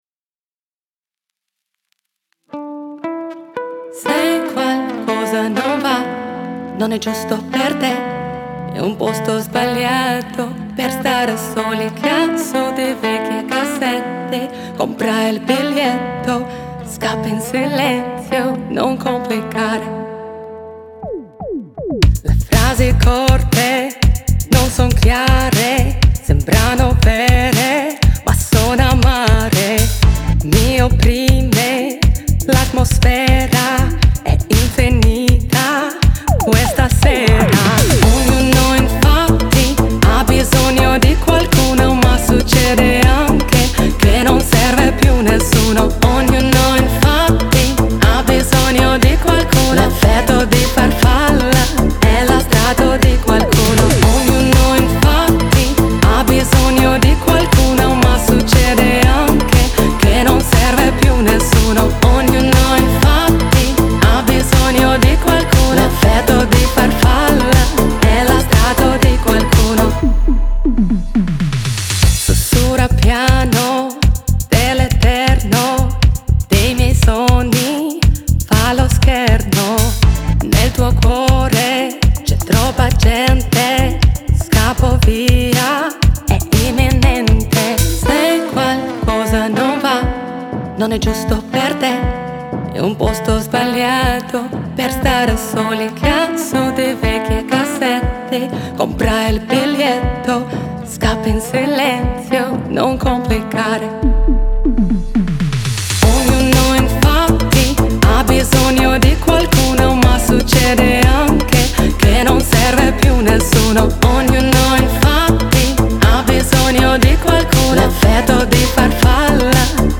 выразительным вокалом